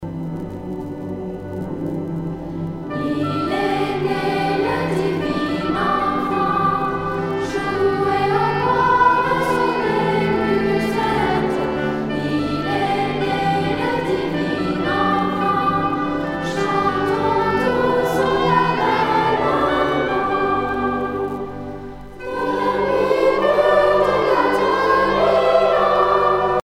Noël
Pièce musicale éditée